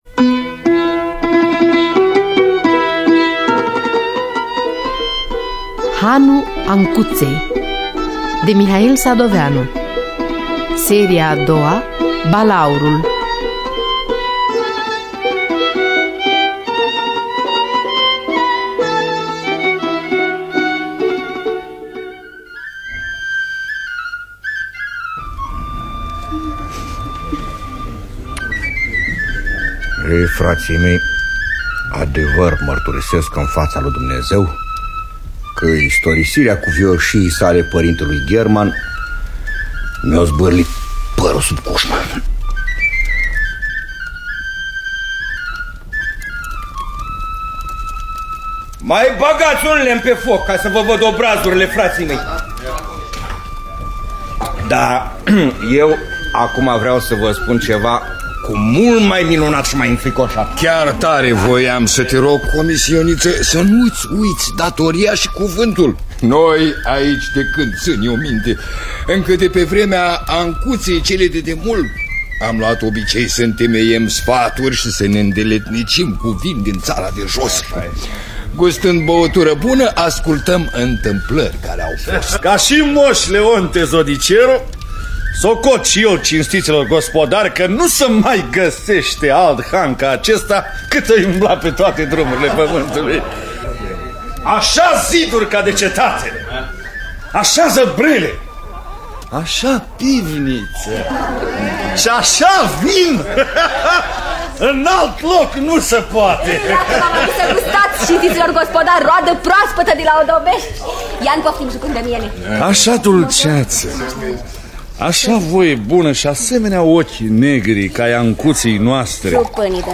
Dramatizarea radiofonică de Valeria Sadoveanu şi Constantin Mitru.